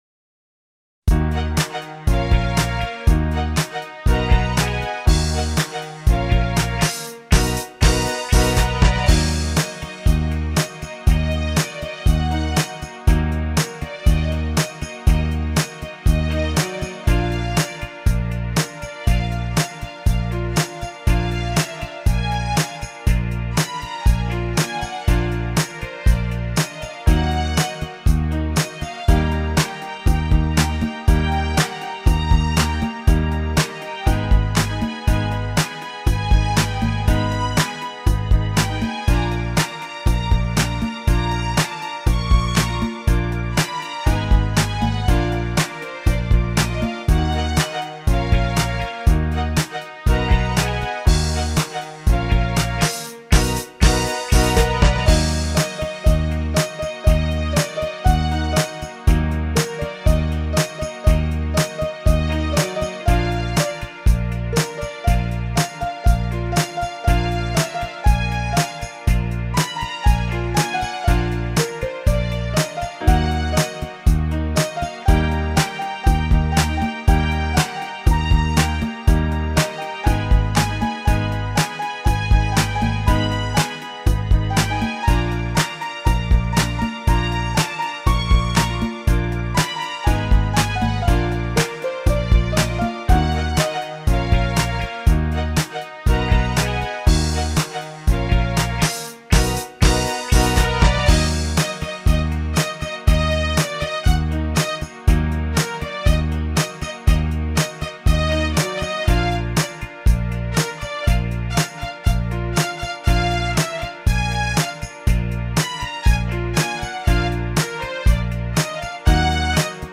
Это просто из опыта освоения синтезатора...